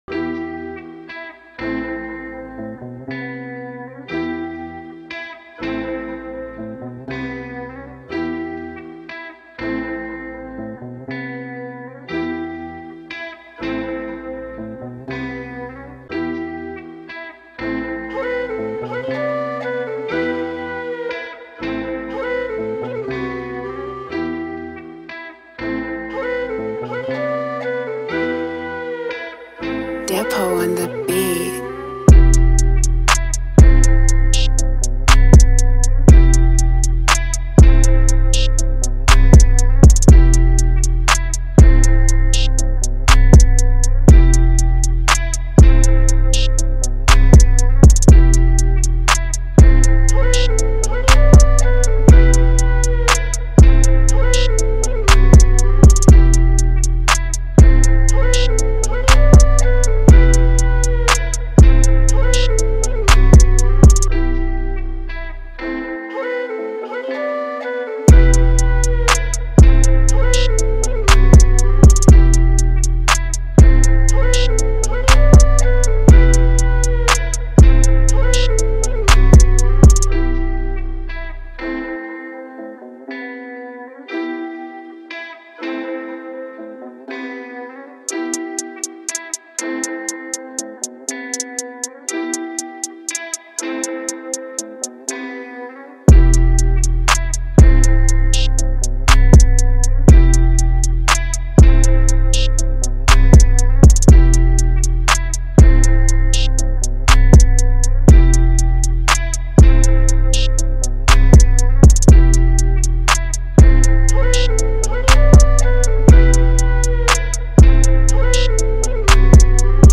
ژانر : ترپ تمپو :120 زمان
مود : مامبل